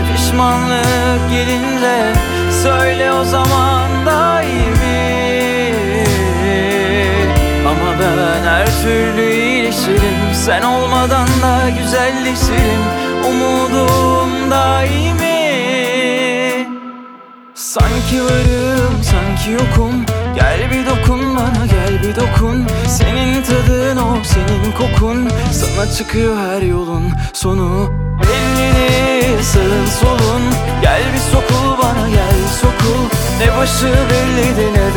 Жанр: Поп / Инди / Альтернатива